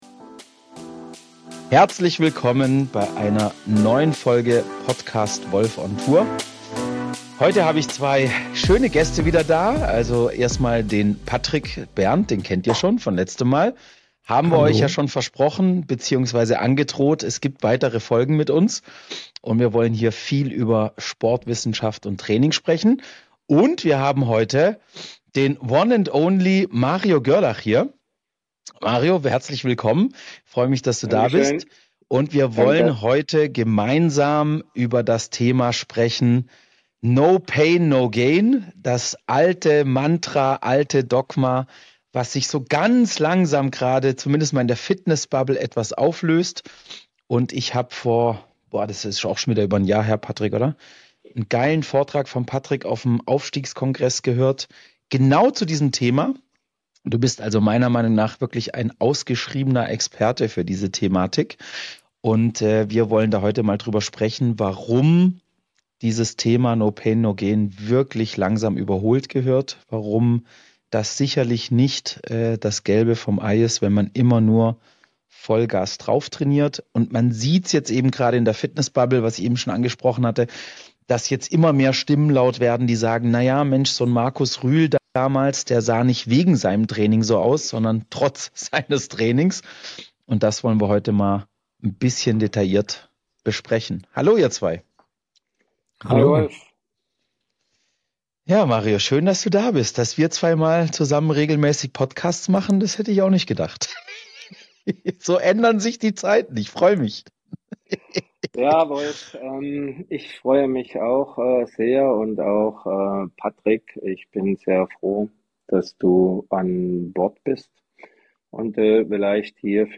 Ein Gespräch zwischen Erfahrung, Forschung und gesundem Menschenverstand.